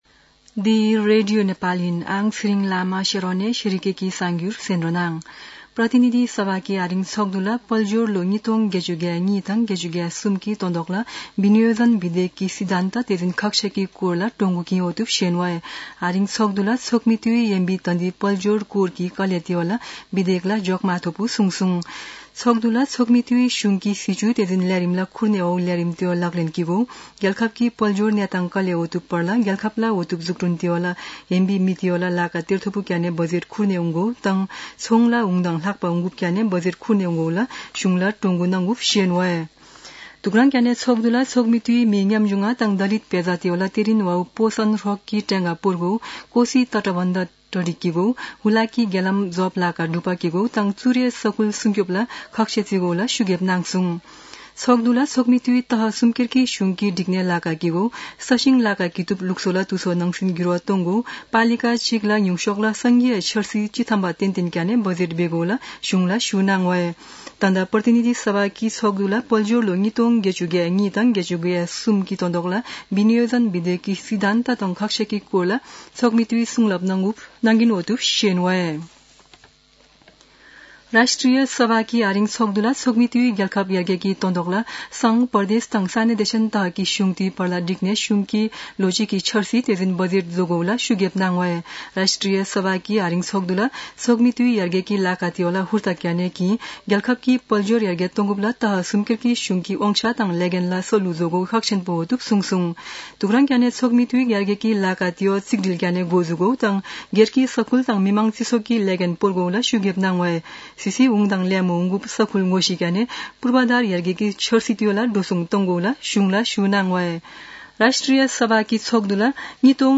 शेर्पा भाषाको समाचार : ३१ वैशाख , २०८२
shearpa-news-1-1.mp3